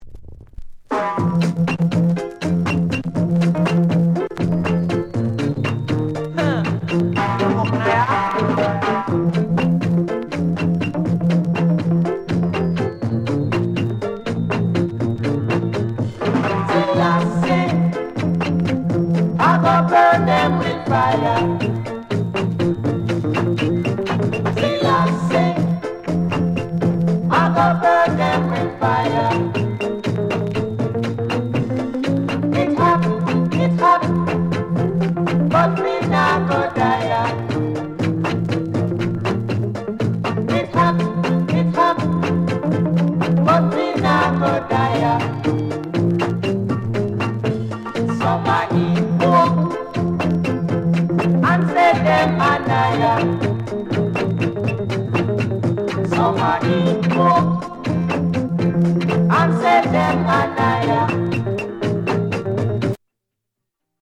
SKINHEAD INST